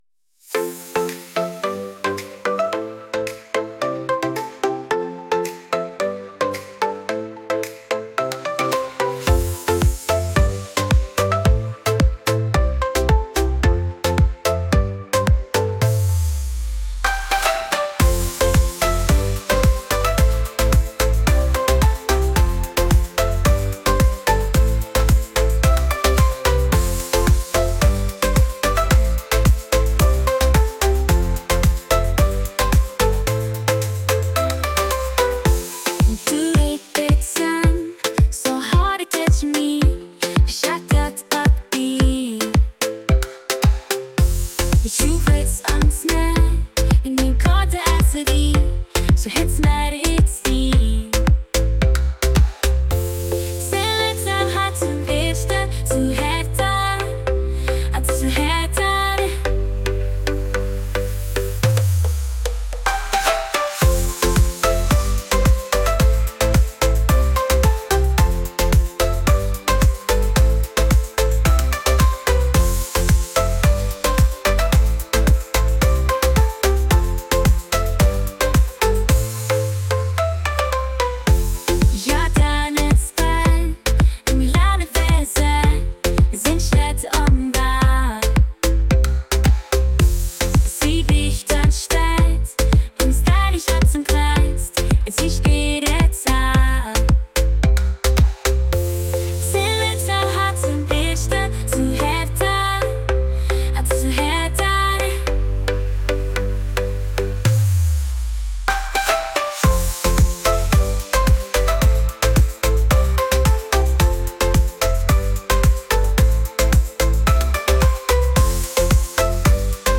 pop | catchy